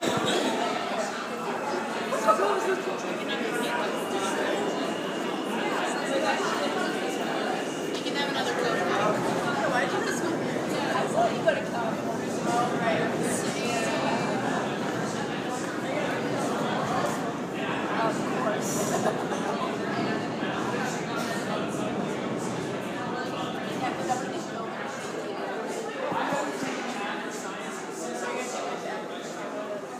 Field Recording
Many people talking in the under ground station (voices are sort of echoing), subway breaks screeching, and people coming down the stairs.
Subway-Station-7th-Ave-E.mp3